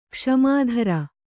♪ kṣmā dhara